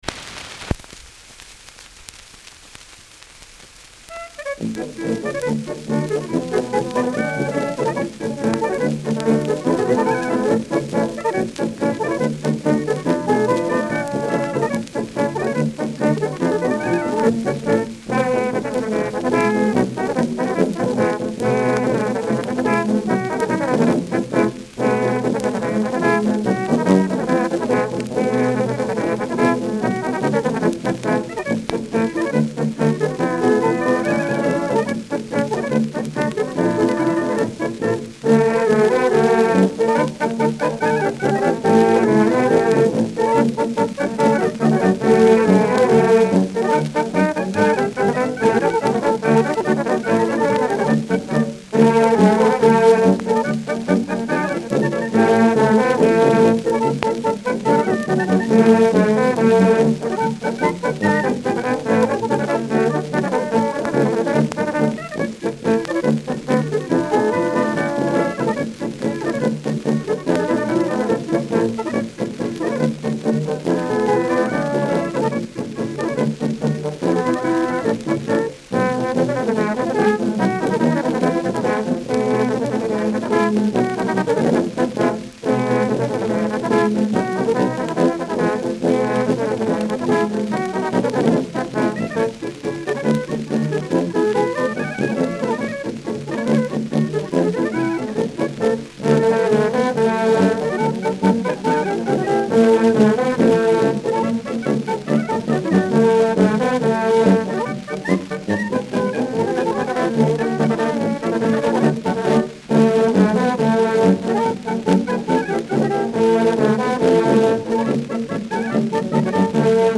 Schellackplatte
Leiern